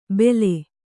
♪ bele